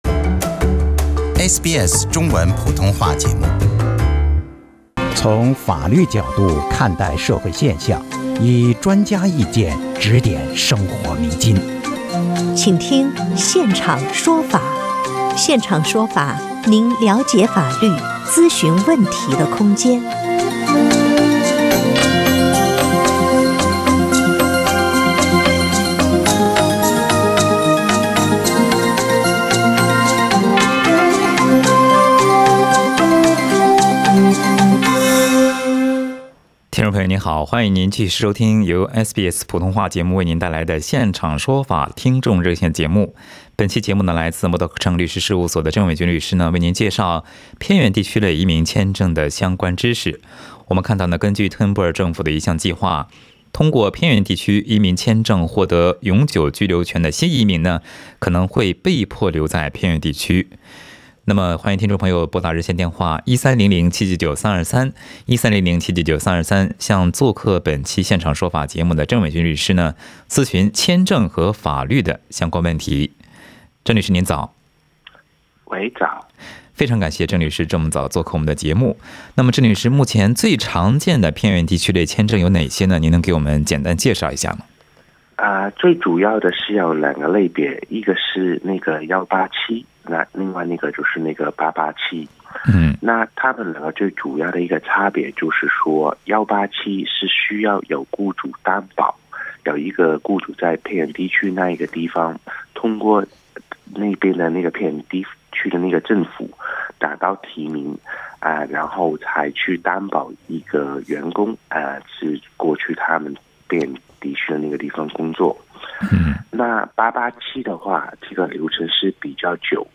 此外，有听众咨询了永久居民在5年内未住满2年续签等待期长，过桥签证打工是否合法，办配偶签证的证明人需要满足什么条件，付费父母移民签证政策，招聘境外牧师签证办理等问题